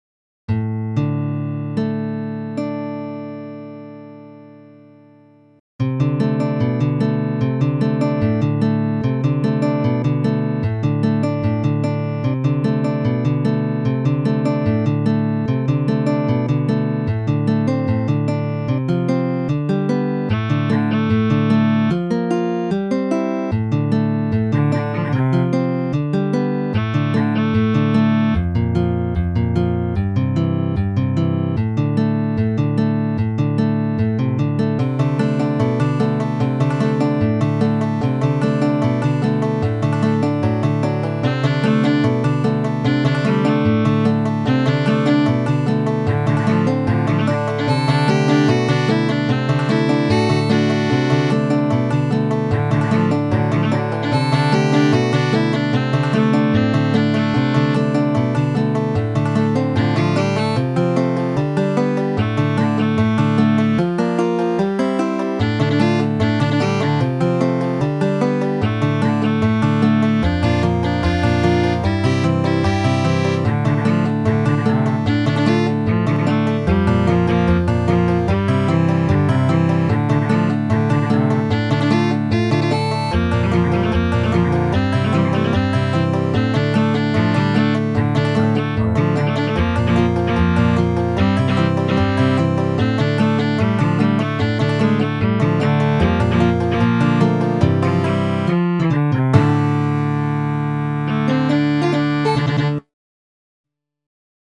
Tried to make a more traditional tune, just to see if I could.
FOLK MUSIC ; BLUEGRASS MUSIC